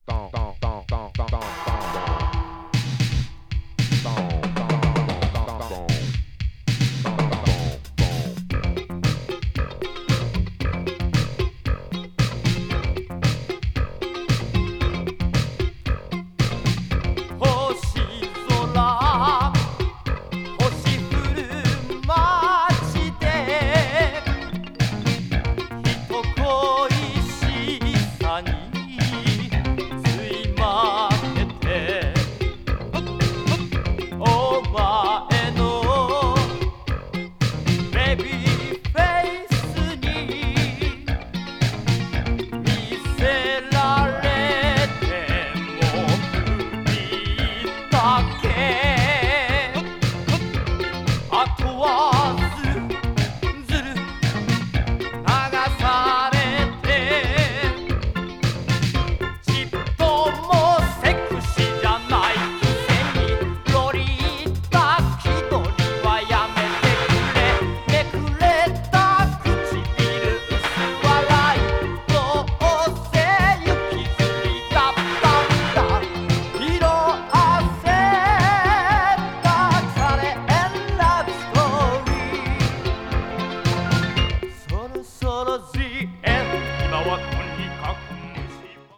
media : EX/EX(わずかにチリノイズが入る箇所あり)
electro   leftfiled   new wave   obscure dance   synth pop